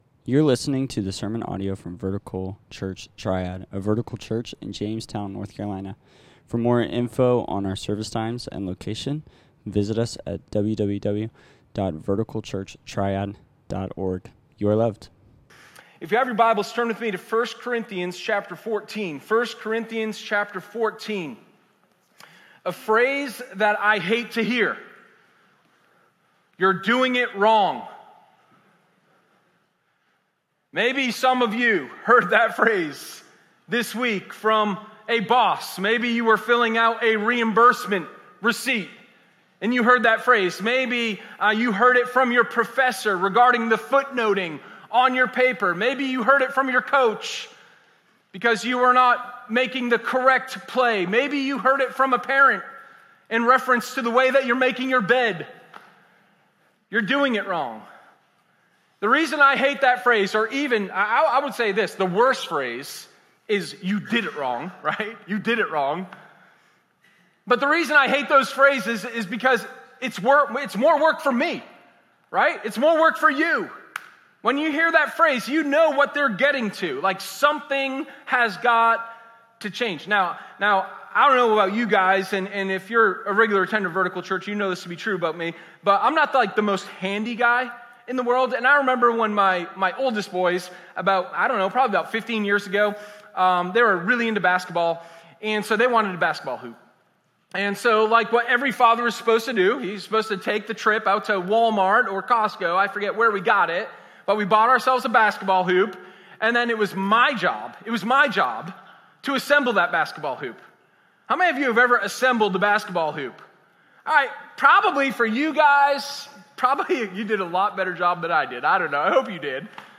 Sermon0925_Youre-Doing-it-Wrong.m4a